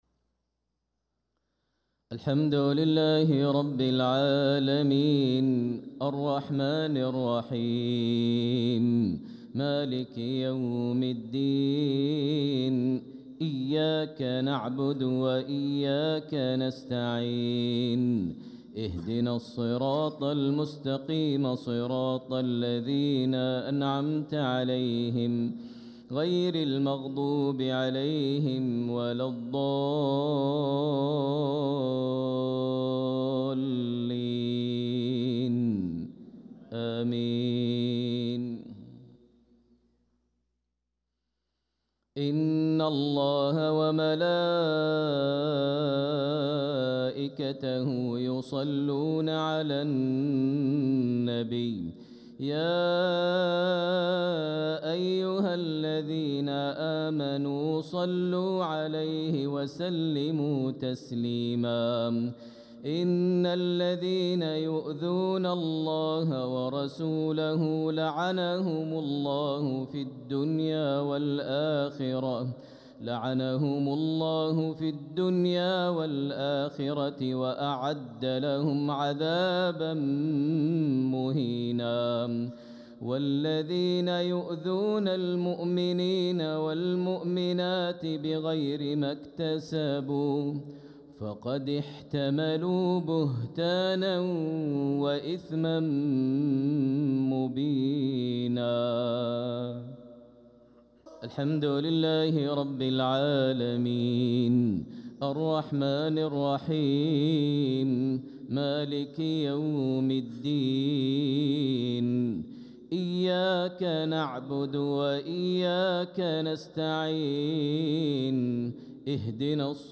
صلاة المغرب للقارئ ماهر المعيقلي 9 ربيع الأول 1446 هـ